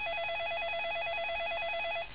ring.wav